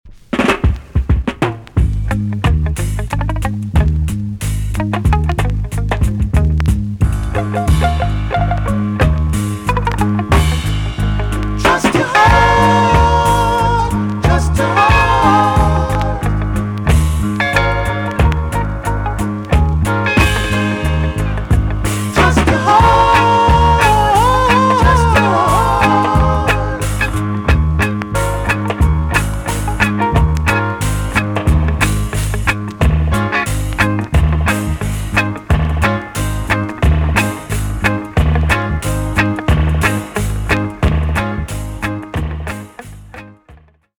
TOP >REGGAE & ROOTS
B.SIDE Version
EX- 音はキレイです。